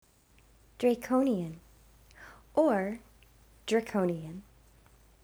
Or, if you prefer, loosen up the first syllable and say "druh KO nee yun." connect this word to others: Today we're checking out the word Draconian , which describes laws that call for harsh, inflexible punishments.
Draconian.wav